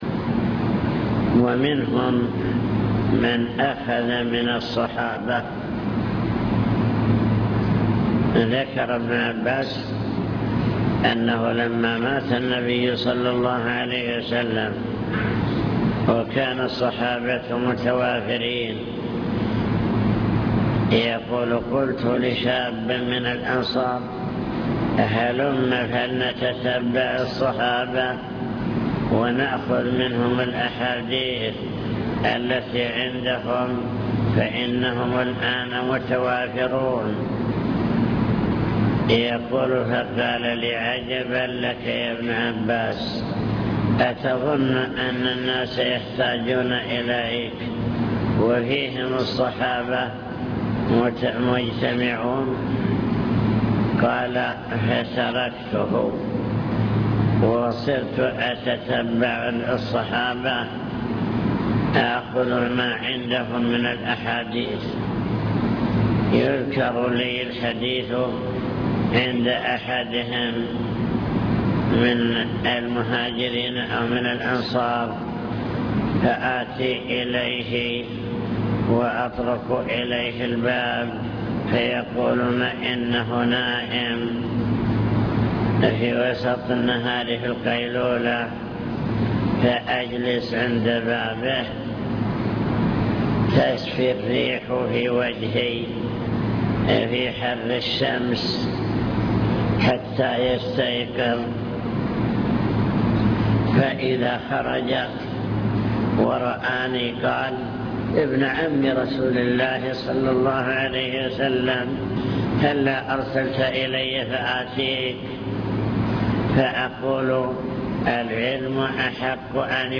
المكتبة الصوتية  تسجيلات - محاضرات ودروس  محاضرات بعنوان: عناية السلف بالحديث الشريف عناية الصحابة بالحديث